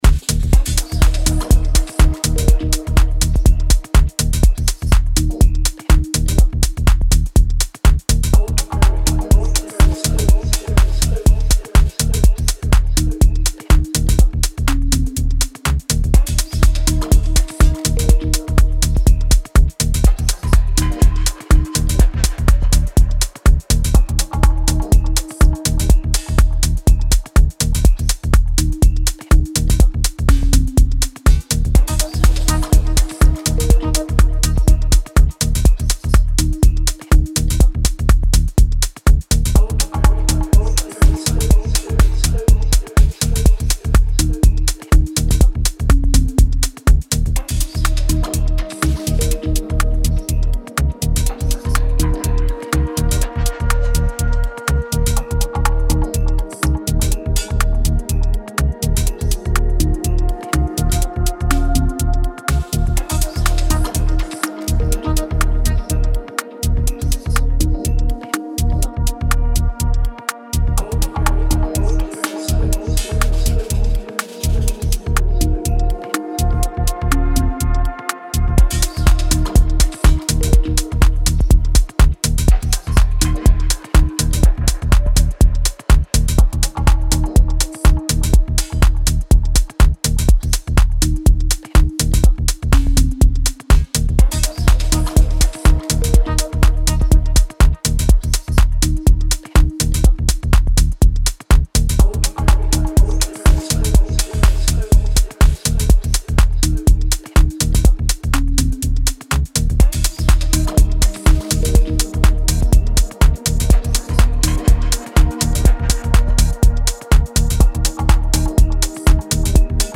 3 original tracks in different moods for the dancefloor